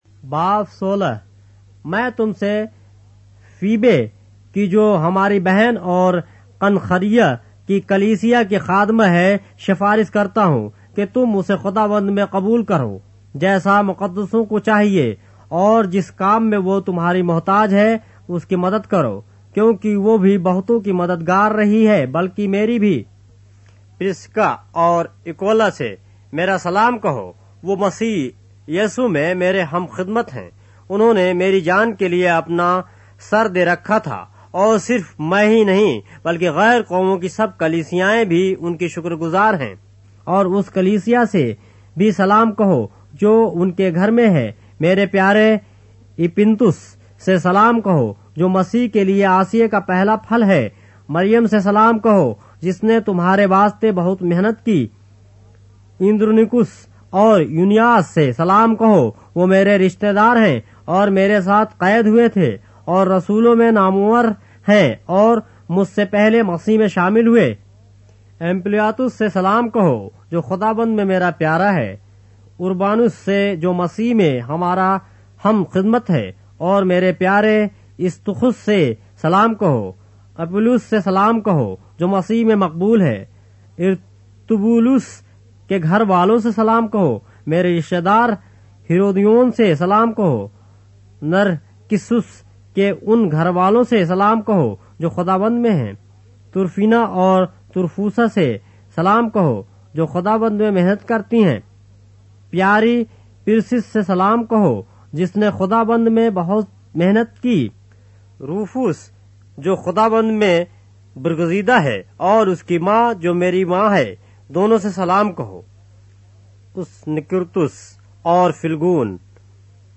اردو بائبل کے باب - آڈیو روایت کے ساتھ - Romans, chapter 16 of the Holy Bible in Urdu